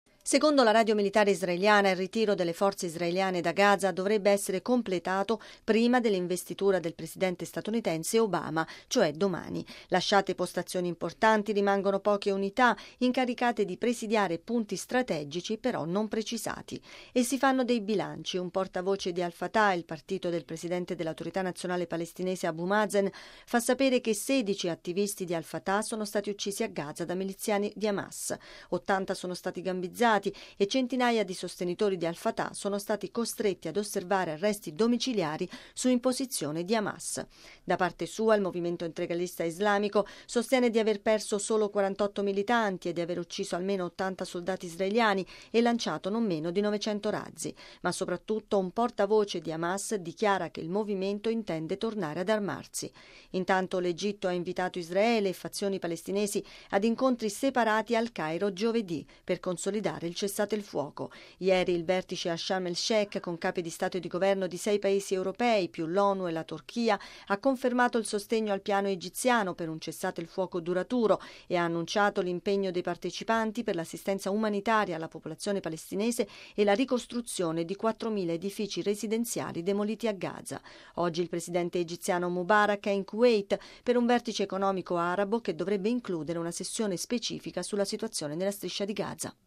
◊   La Striscia di Gaza ha trascorso una seconda notte di calma grazie al cessate-il-fuoco dichiarato separatamente da Israele e da Hamas, mentre continua il ritiro graduale delle truppe israeliane dalla Striscia, dove per tre settimane è andata avanti l’operazione militare "Piombo fuso", che ha causato la morte di oltre 1.300 palestinesi. Il servizio